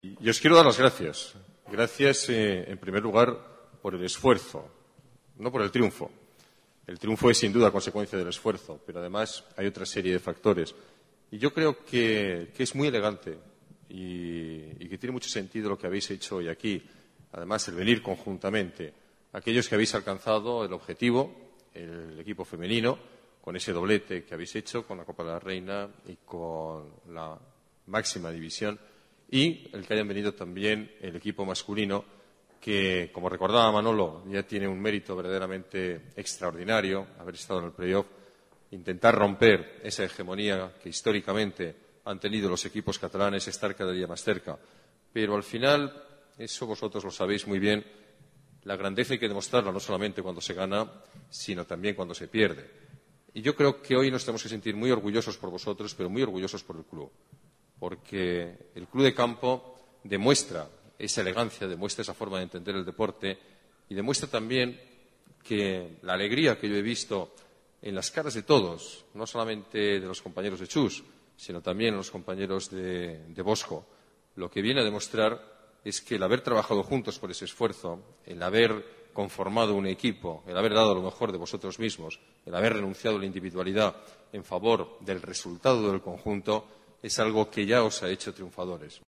Nueva ventana:Declaraciones alcalde, Alberto Ruiz-Gallardón: felicitación triunfo equipos hockey femenino y masculino